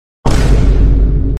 VINEbOom - Botón de Efecto Sonoro